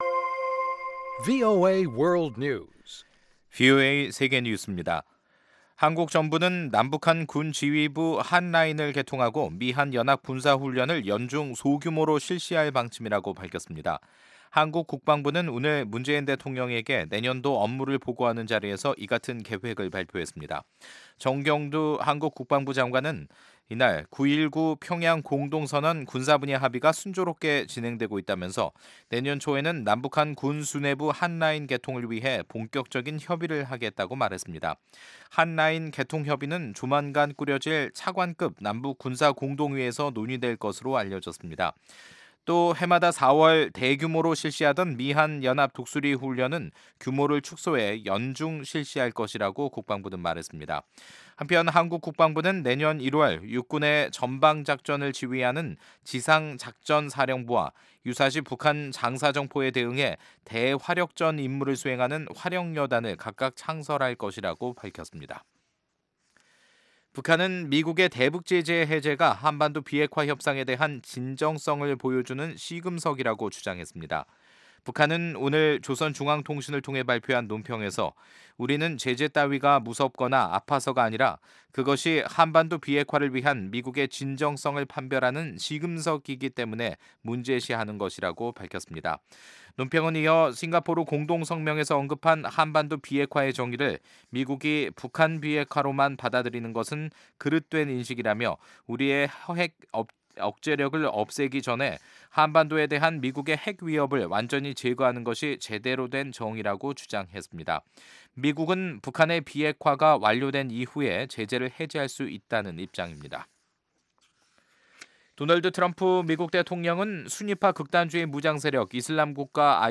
VOA 한국어 간판 뉴스 프로그램 '뉴스 투데이', 2018년 12월 20일 2부 방송입니다. 북한 정권에 소송을 제기한 오토 웜비어 씨의 가족들이 미 연방법원에 출석해 북한이 아들의 사망에 책임이 있다고 주장했습니다. 미국의 전직 관리들은 인도적 지원을 위한 미국의 북한 여행 허용을 시사한 스티븐 비건 국무부 대북정책 특별대표의 발언을 미-북 간 교착 국면을 풀어보려는 시도로 풀이했습니다.